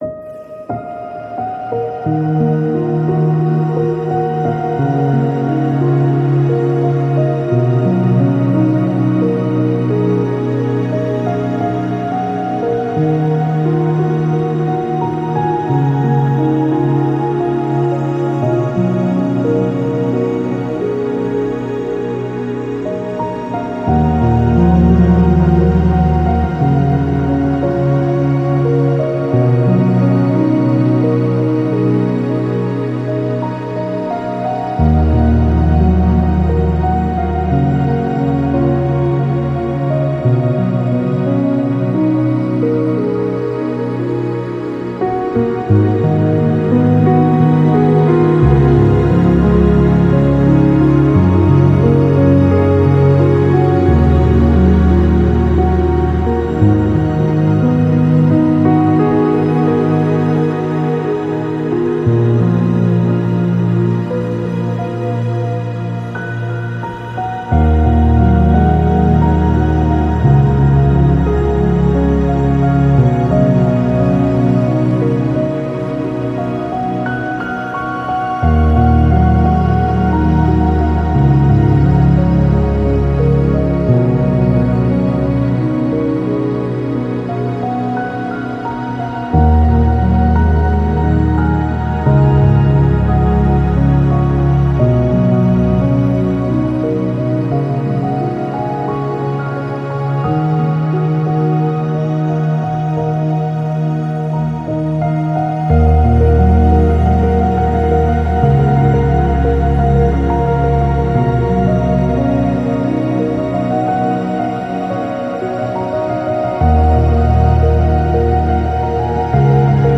Acoustic
ambient piano